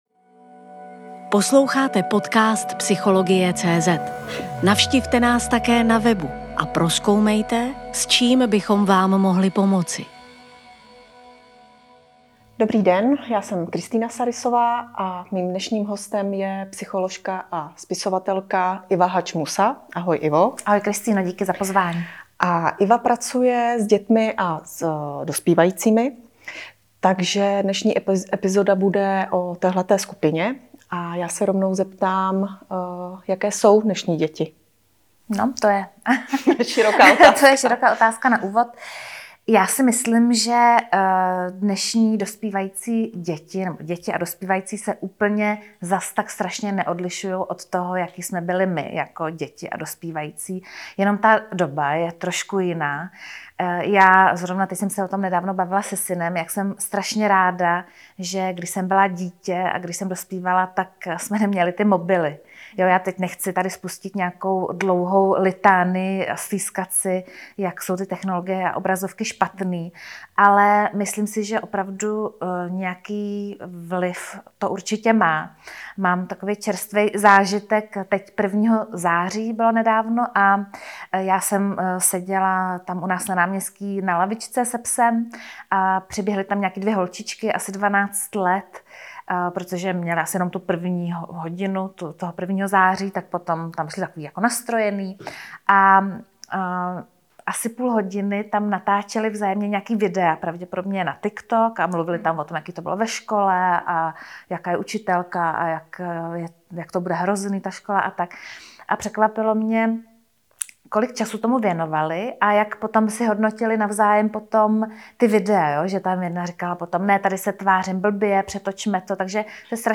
rozhovoru